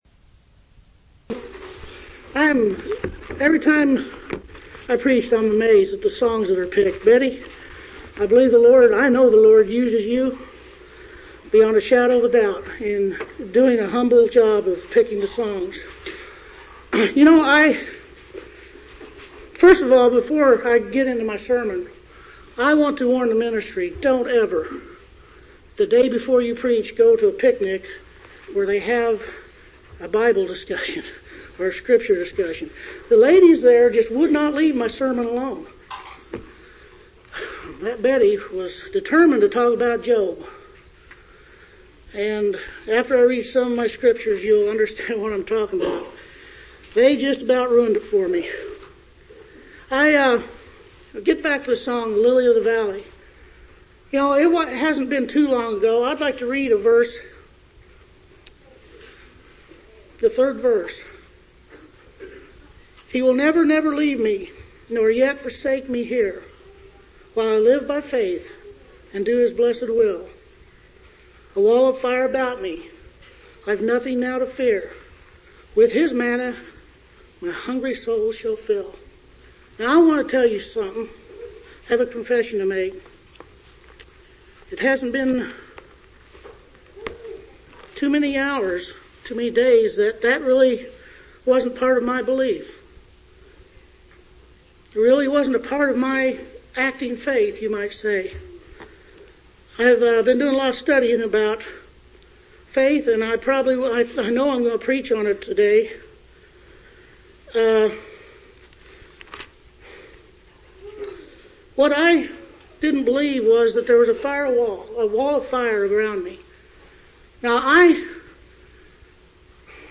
3/27/1983 Location: Phoenix Local Event